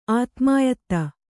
♪ ātmāyatta